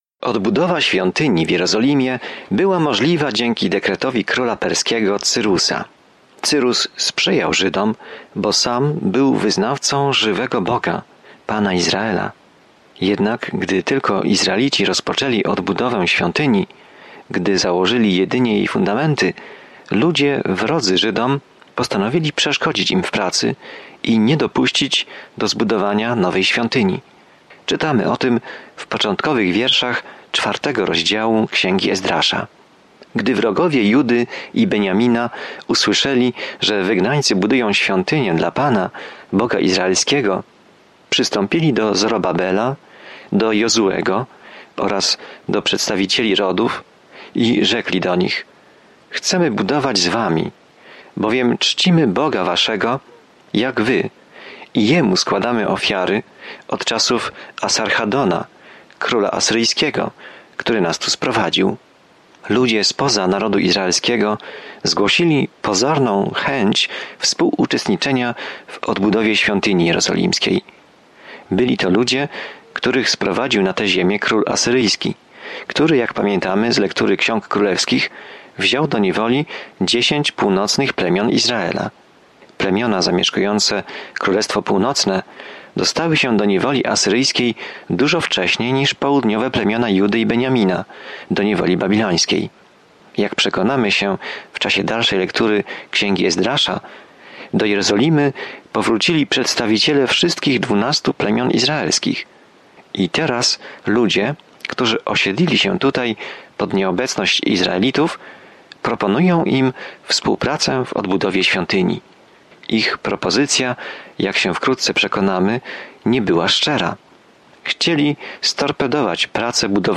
Pismo Święte Ezdrasza 4 Ezdrasza 5 Ezdrasza 6:1-5 Dzień 2 Rozpocznij ten plan Dzień 4 O tym planie Izraelici po powrocie z niewoli odbudowują świątynię w Jerozolimie, a uczony w Piśmie imieniem Ezra uczy ich, jak ponownie przestrzegać praw Bożych. Codziennie podróżuj przez Ezdrasza, słuchając studium audio i czytając wybrane wersety ze słowa Bożego.